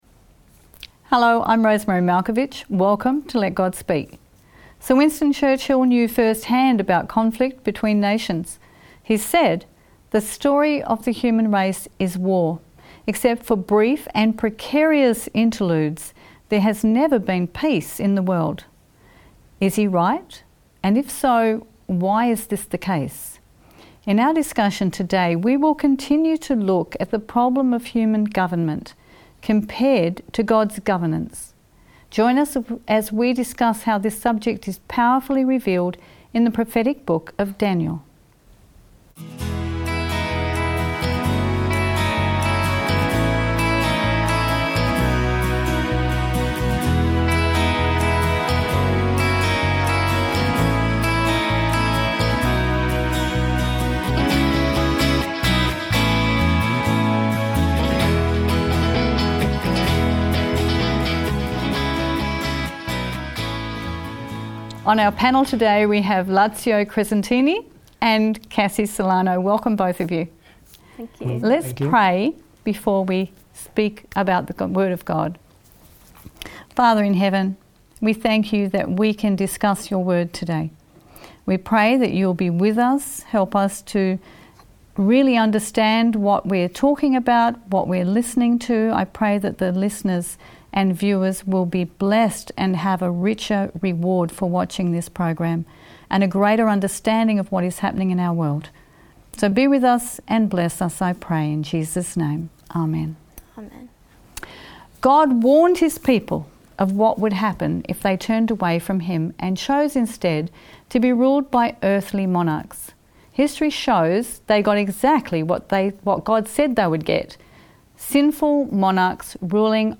Join us for lively discussions and deep insights into the Word of God. "Let God Speak" helps the Bible come alive in a new way as you study along with them.